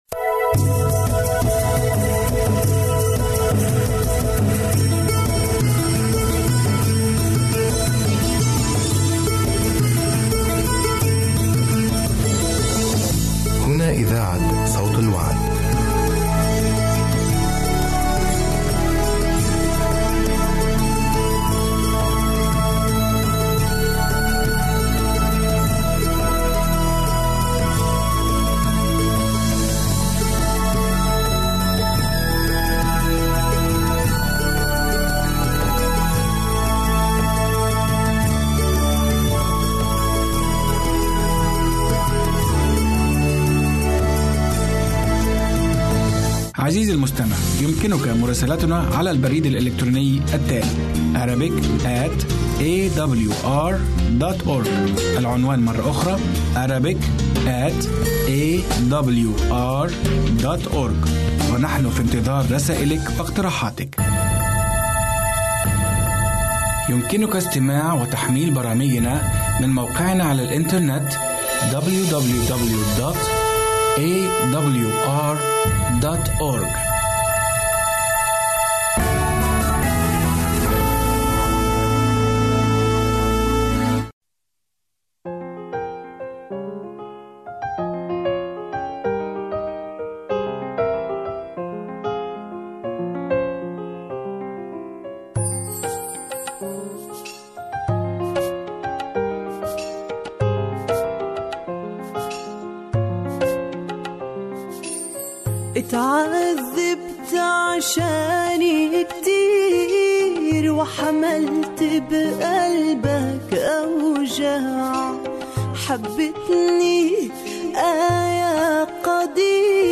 برنامج إذاعي يومي باللغة العربية AWR يتضمن برامج مقابلة ، حياة عائلية ، جولة مع أمثال المسيح.